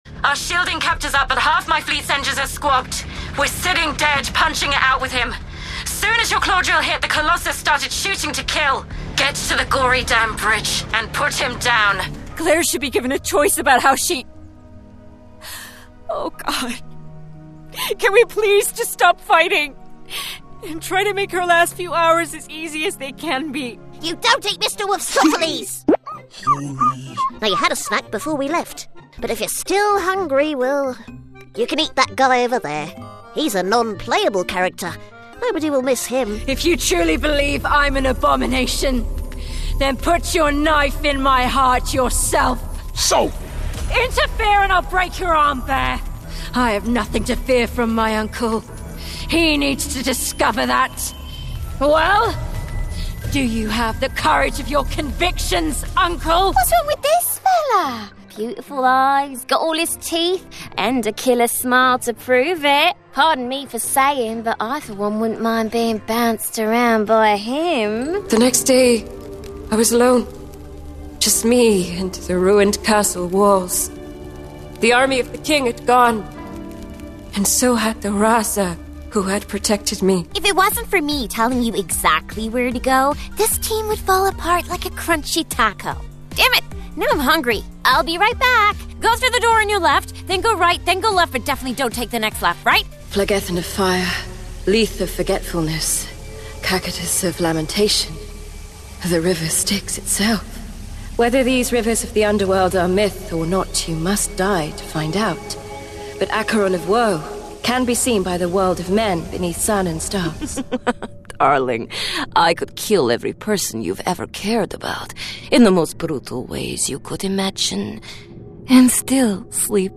Videojuegos
Micrófonos: Neumann U87, Neumann TLM 102, Sennheiser MKH 416
Cabina: Cabina vocal de doble pared a medida de Session Booth con paneles añadidos de EQ Acoustics, Auralex y Clearsonic.